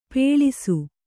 ♪ pēḷisu